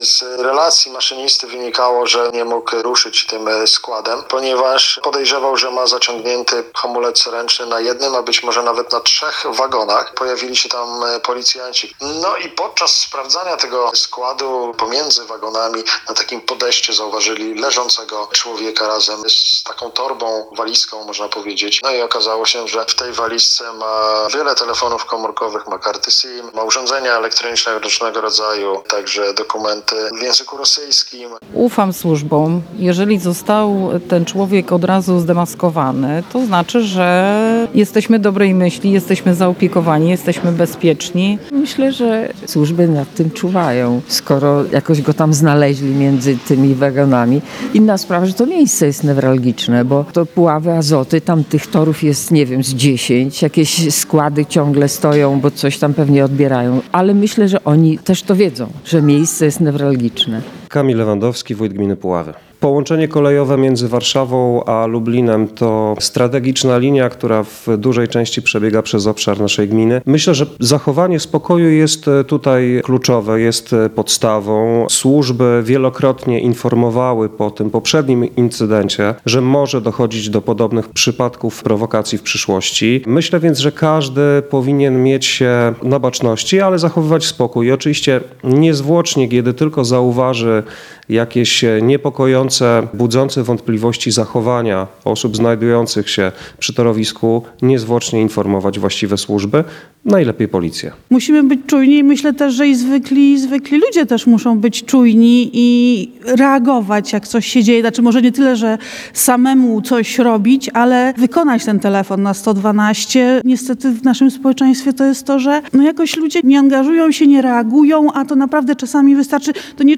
– Ufam służbom – mówi jedna z mieszkanek.